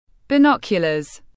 Binoculars /bɪˈnɒk.jə.ləz/